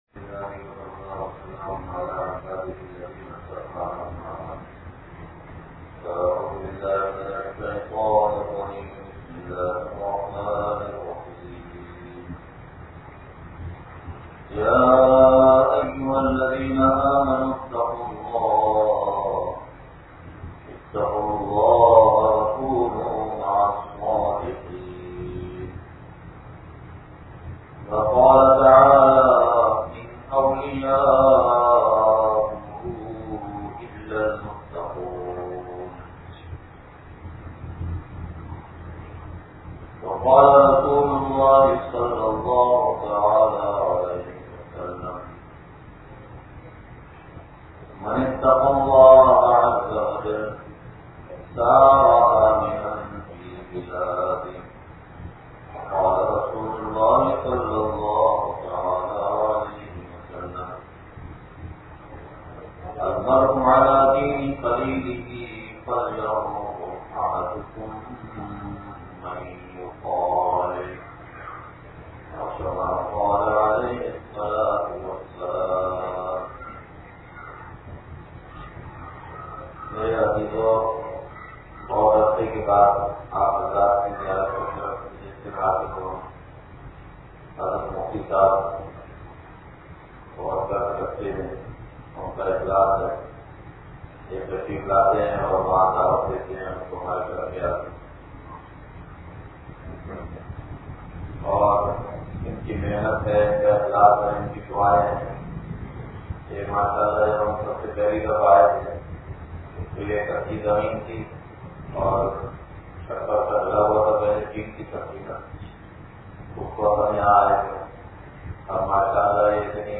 بیان مسجد فاروق اعظم